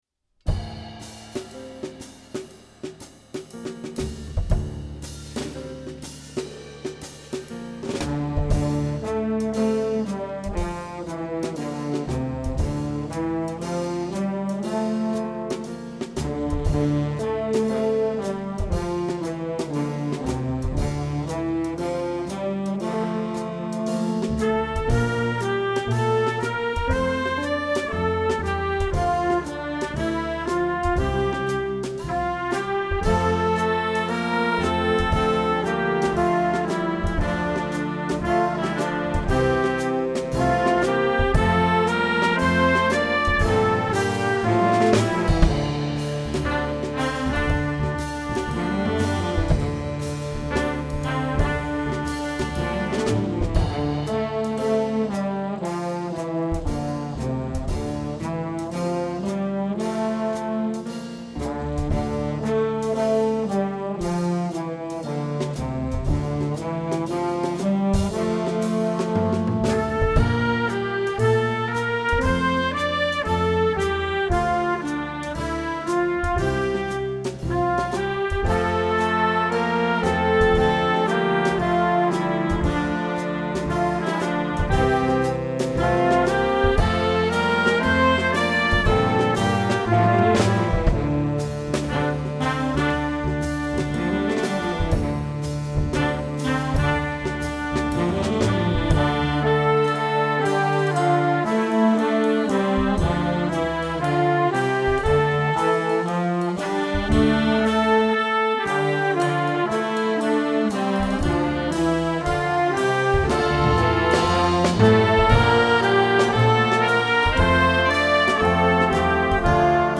Style: Dark Accessible Jazz
Instrumentation: Standard Big Band